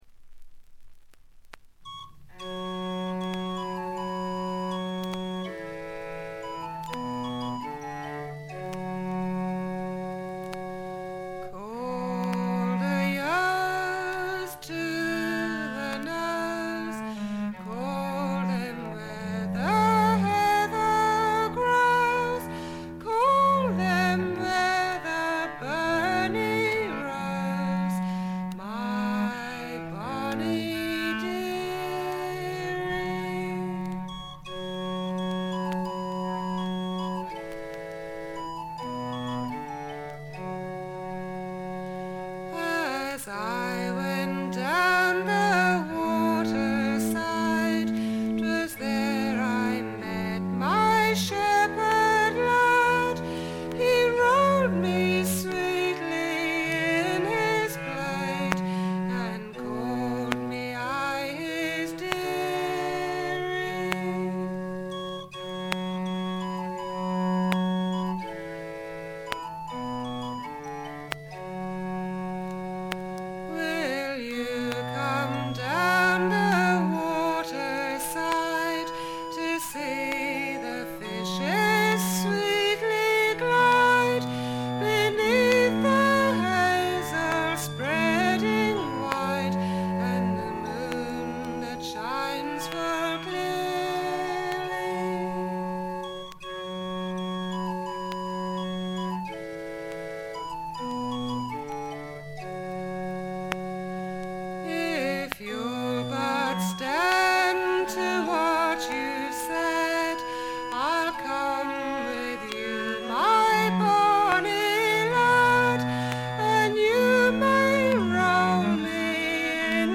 B2目立つ周回ノイズ。これ以外も軽い周回ノイズ、チリプチ、プツ音等多め大きめです。
試聴曲は現品からの取り込み音源です。